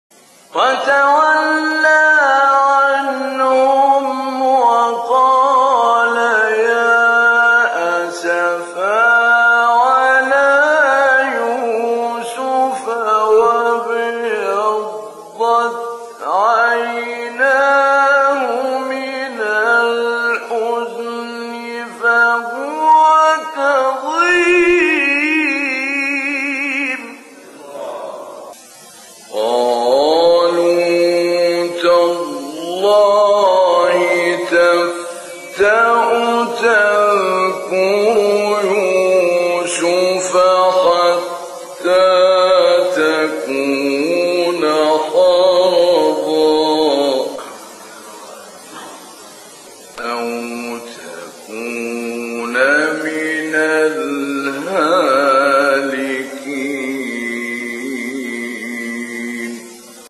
گروه شبکه اجتماعی: فرازهای صوتی از تلاوت قاریان به‌نام مصری را می‌شنوید.